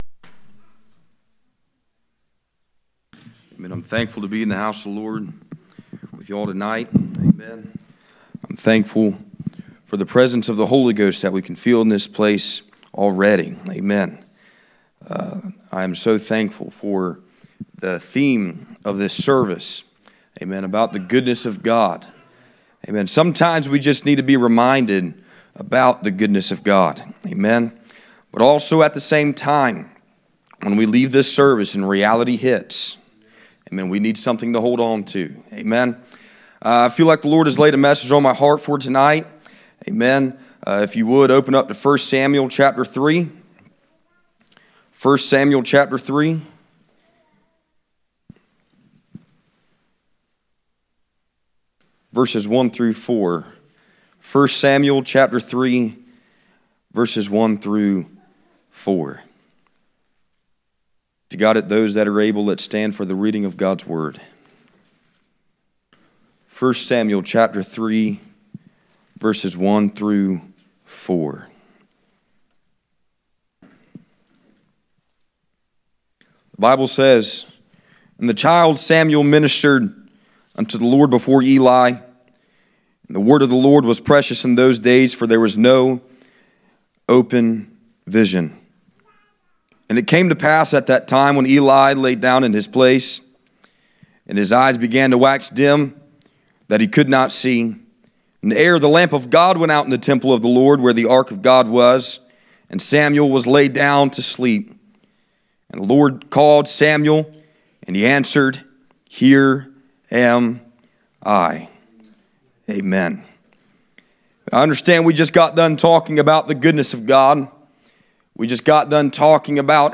1 Samuel 3:1-4 Service Type: Midweek Meeting %todo_render% « The need of the Holy Ghost